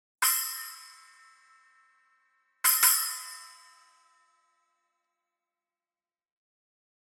Тарелки серии Custom обладают широким частотным диапазоном, теплым плотным звуком и выдающейся музыкальностью.
Masterwork 6 Custom Splash sample
Custom-Splash-6.mp3